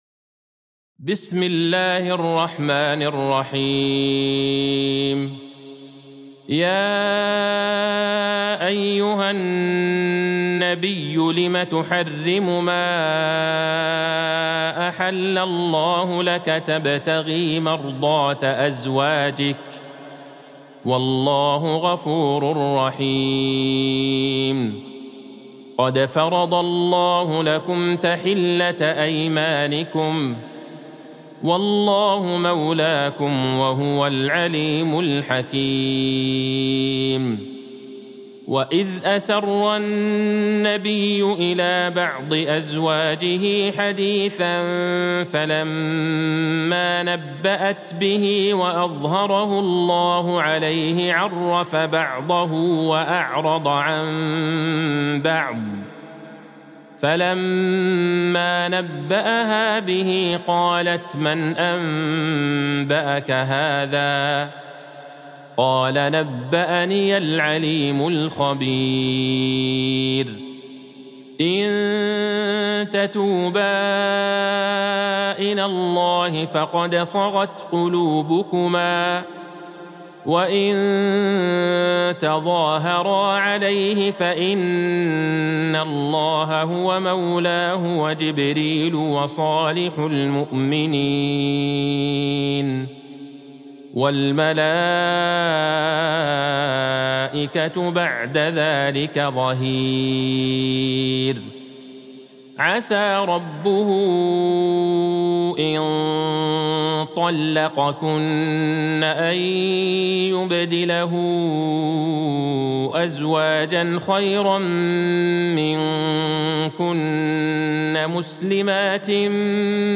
سورة التحريم Surat At-Tahrim | مصحف المقارئ القرآنية > الختمة المرتلة ( مصحف المقارئ القرآنية) للشيخ عبدالله البعيجان > المصحف - تلاوات الحرمين